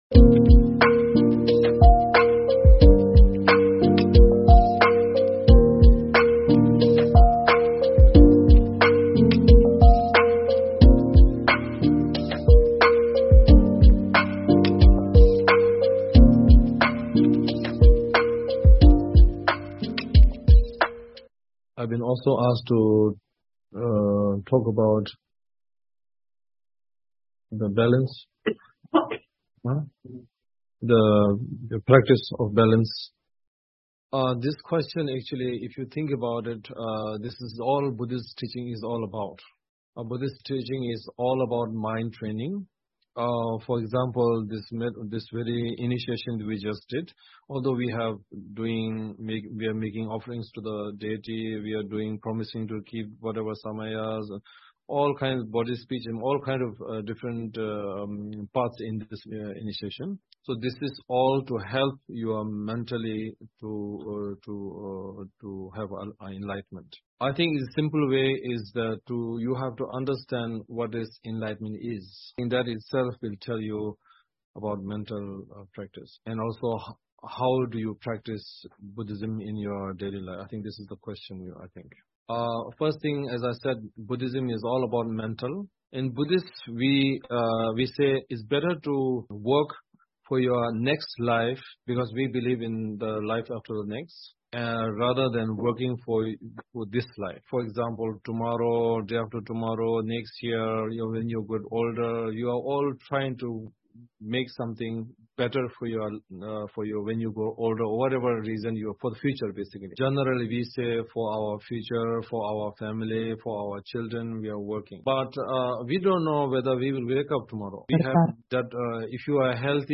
1.Inner Balance_H.H. the 43nd Sakya Trizin's Dharma Teaching Given in 2023_The Sakya Tradition
Topic: Inner Balance Author: H.H. the 43rd Sakya Trizin Venue: Sangchen Norbu Ling, Montroyo, Spain Video and Audio Source: Sangchen Norbu Ling Youtube